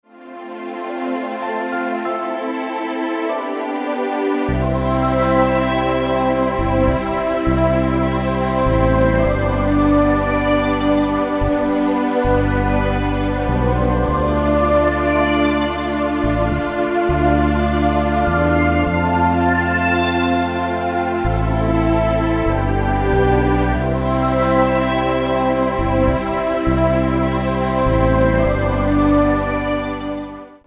Piękna harmonijna muzyka do masżu.